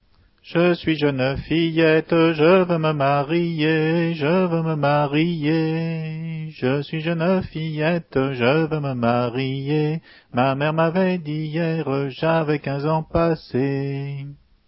Hanter dro
Entendu au concours de la Bogue d'Or